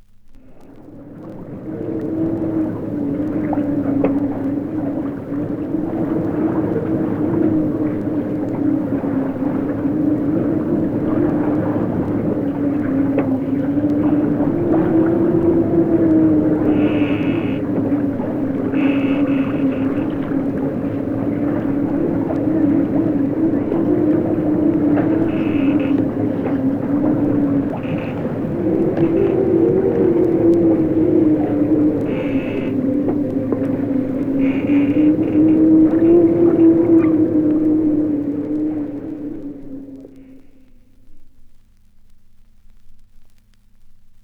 • wind howling in ship's rigging.wav
Recorded from Sound Effects - Death and Horror rare BBC records and tapes vinyl, vol. 13, 1977. Equipment used: TTA05USB akai player and focusrite 8i8 interface, using an SSL limiter for some dimmed s...
wind_howling_in_ships_rigging_2zk.wav